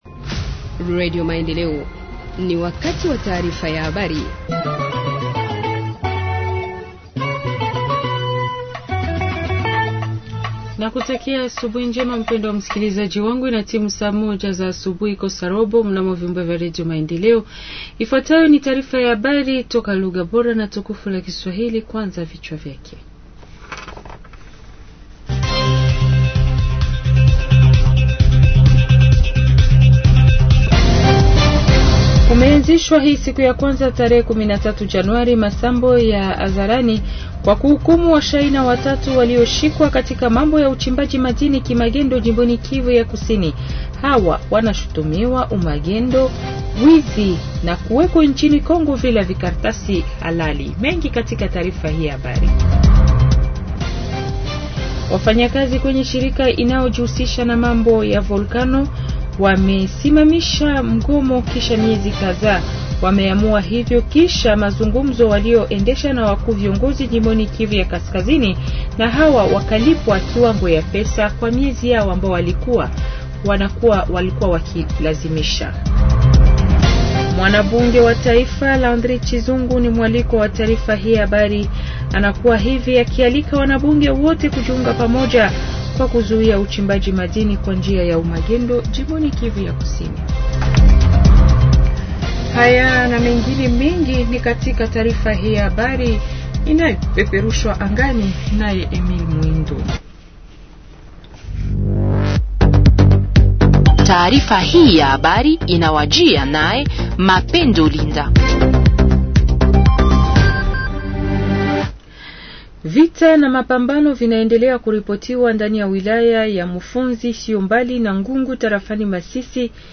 Journal Swahili du 14 janvier 2025 – Radio Maendeleo